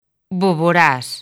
Transcripción fonética
boβoˈɾas, boˈβɾas